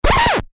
Звуки скретча
Звук остановки трека: скретч, запись, диск